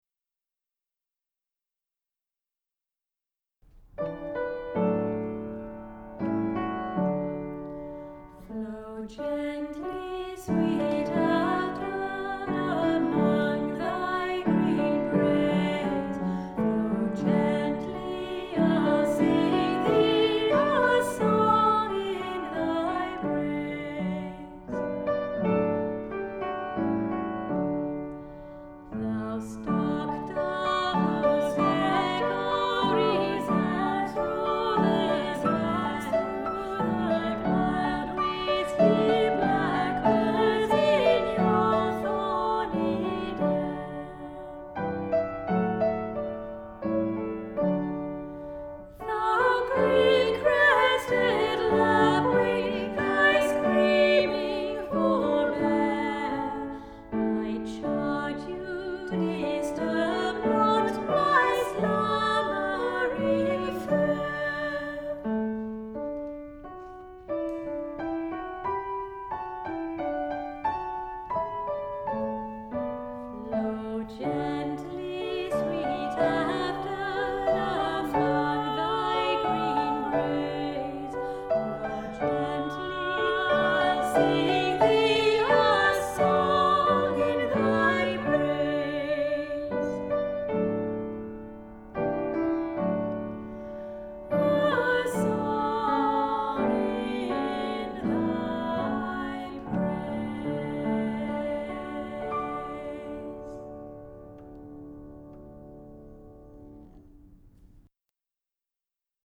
Voicing: Choir